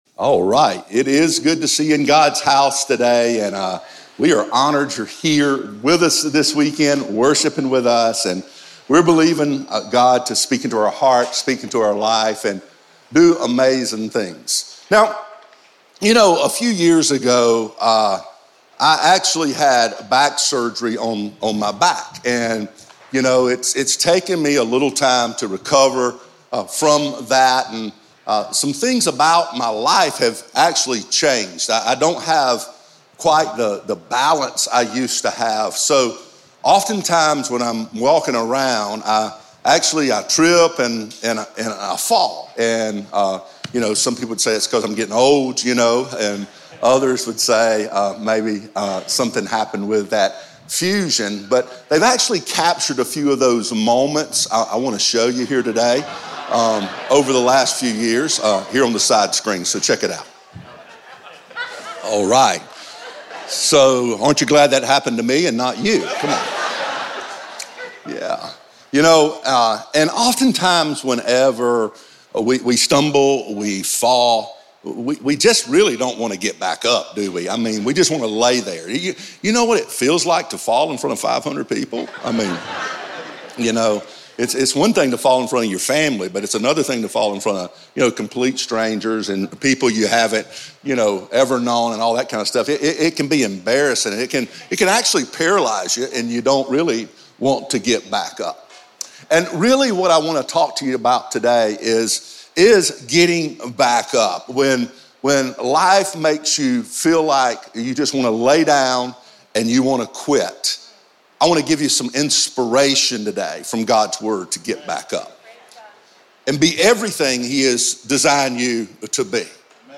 a dynamic, high-energy speaker with a heart and vision to reach the world.